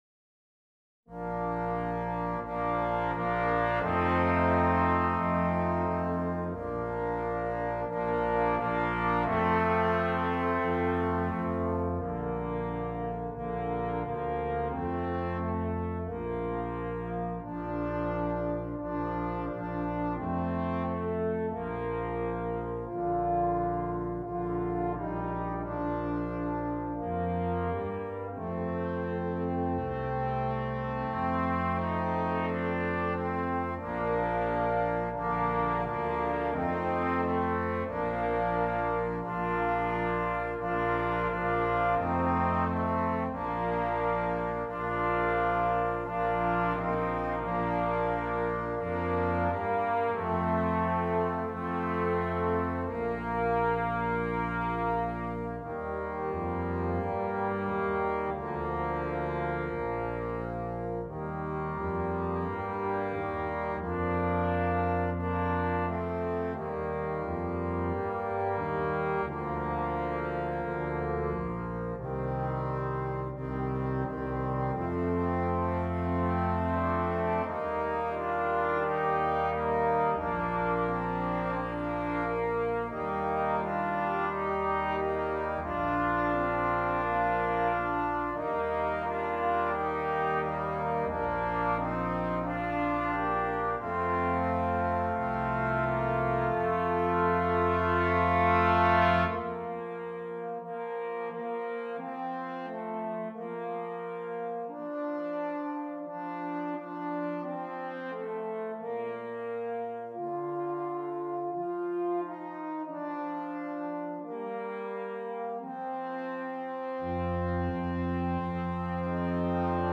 Brass Quintet
calming, simple, and enjoyable to perform and listen to.